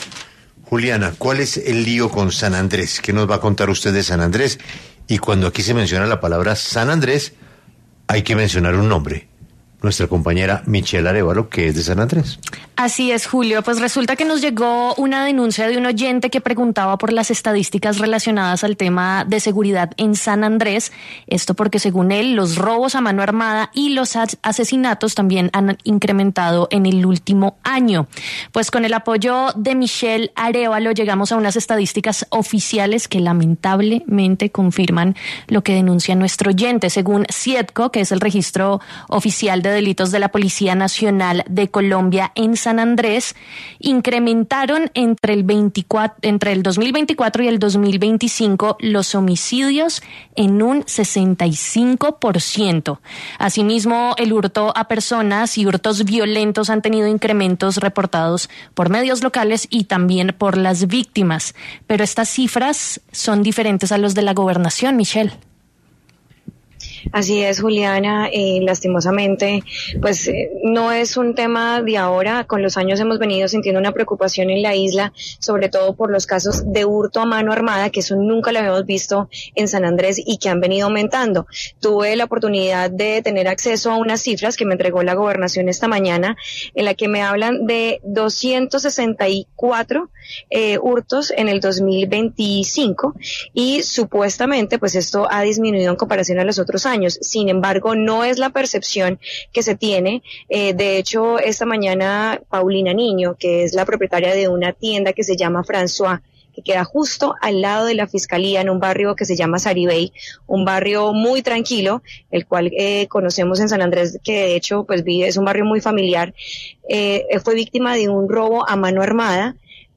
En diálogo con 6AM W, Ana Carvajalino Peinado, secretaria de seguridad y convivencia ciudadana de San Andrés aseguró que “se han tomado todas las prevenciones en conjunto con las fuerzas militares del Departamento para desterrar este flagelo que nos ha venido azotando”.